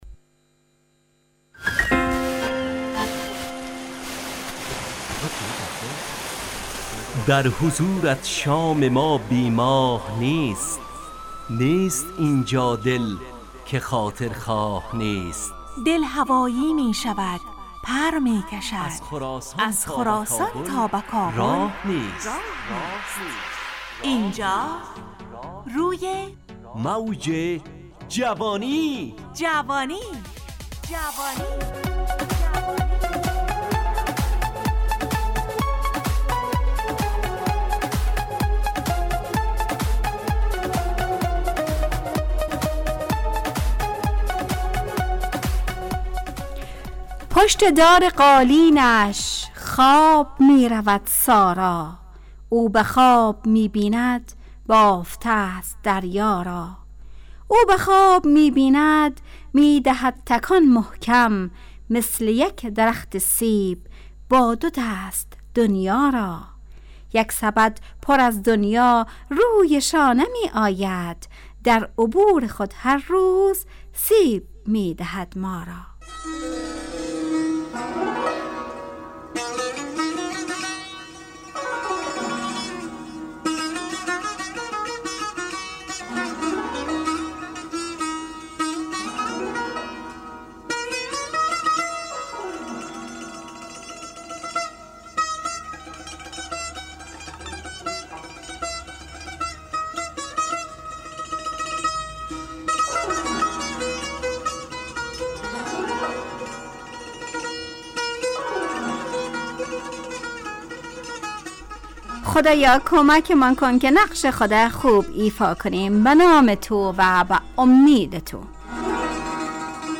همراه با ترانه و موسیقی مدت برنامه 70 دقیقه . بحث محوری این هفته (نقش) تهیه کننده